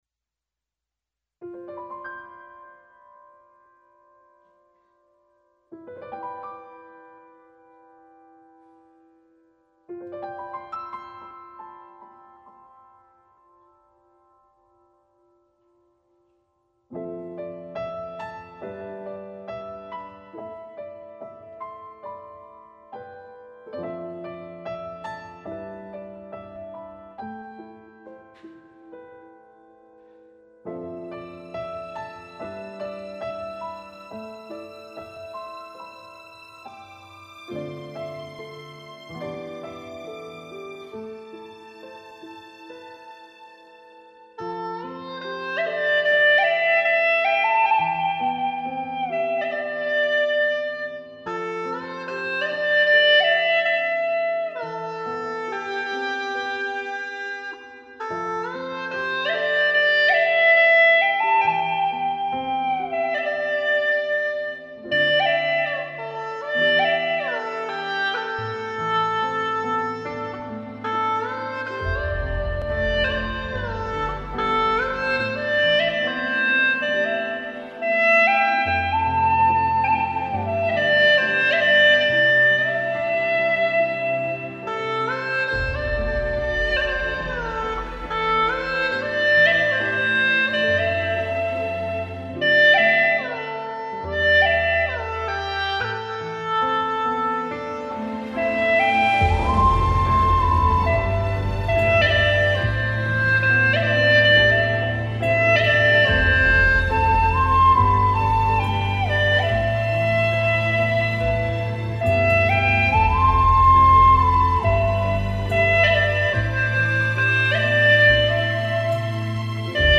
调式 : C 曲类 : 影视